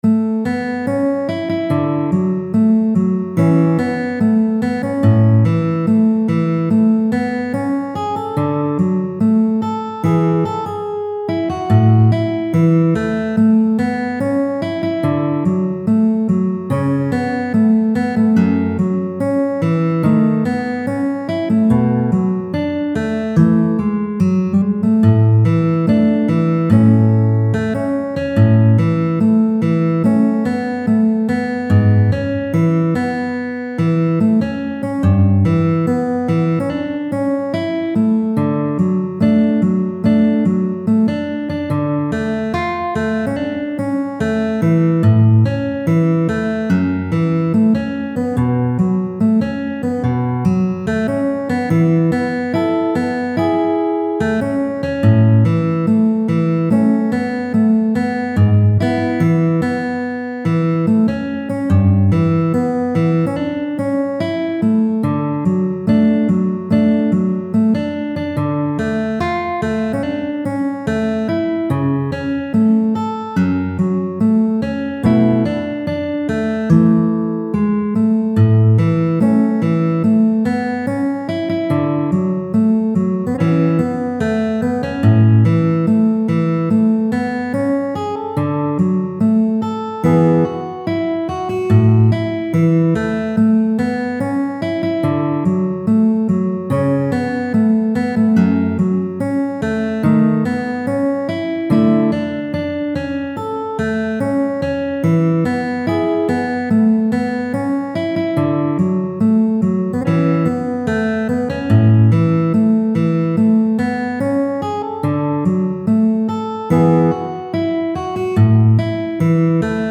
G调指法编配，变调夹2品，简单好听易上手